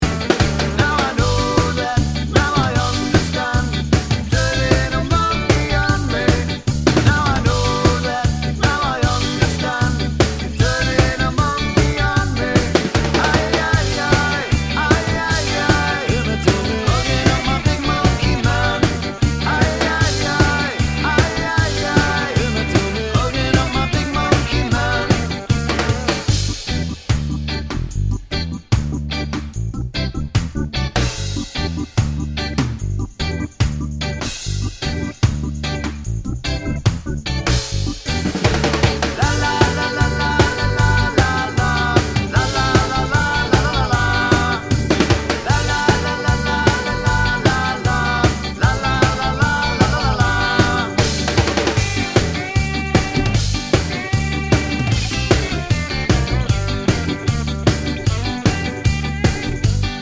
no Backing Vocals Ska 2:34 Buy £1.50